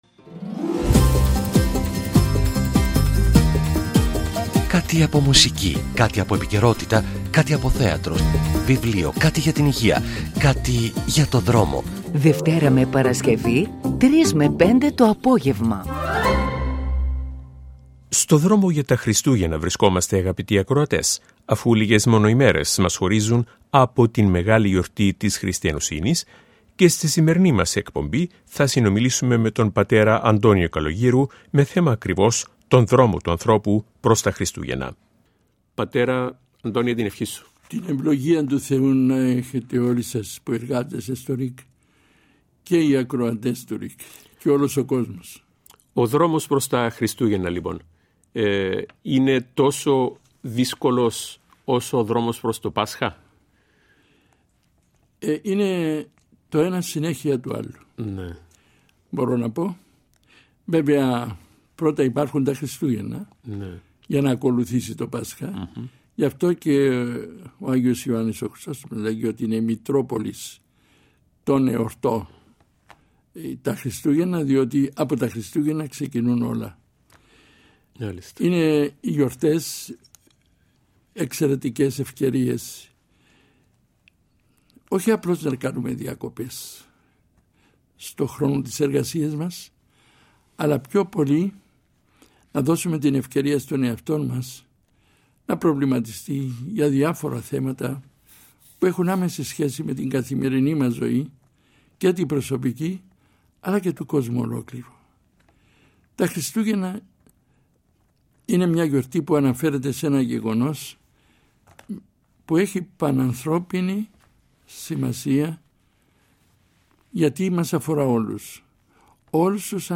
Μαγκαζίνο, με θέματα ενημερωτικά, κοινωνικά, ψυχαγωγικά.